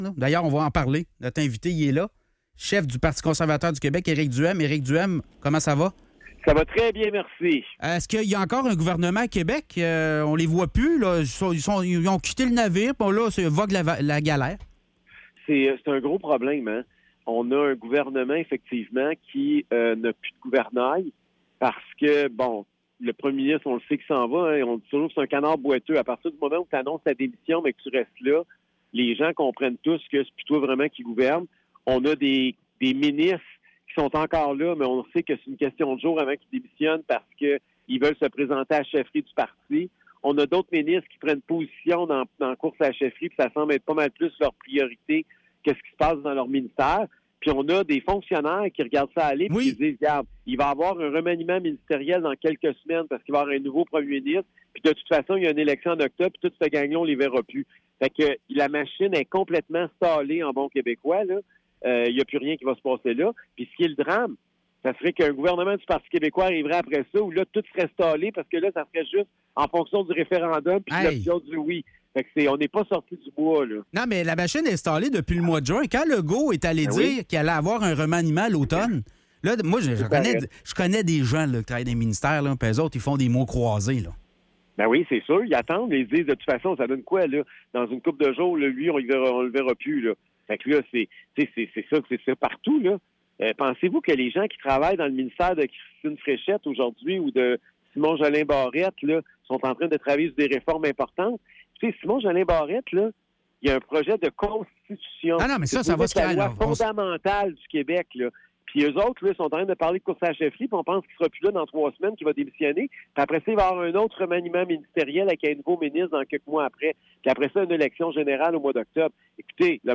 Entrevue avec Éric Duhaime, chef du PCQ